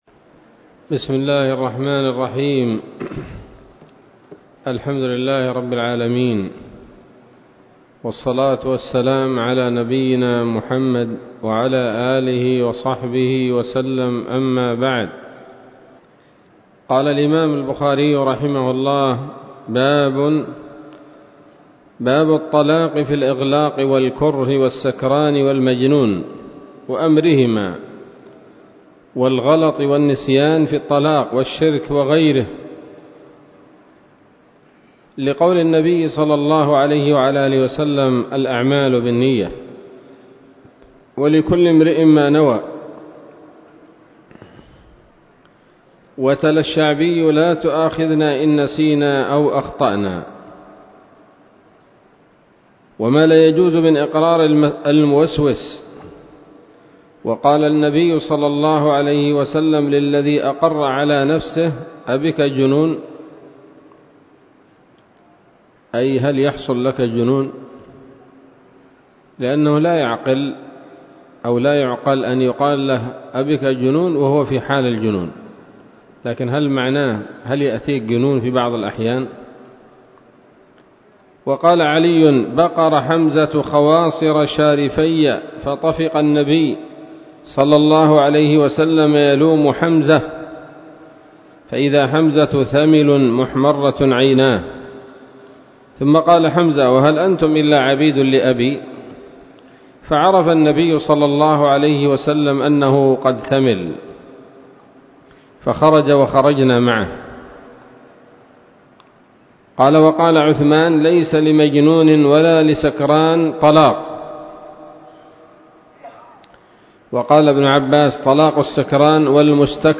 الدرس التاسع من كتاب الطلاق من صحيح الإمام البخاري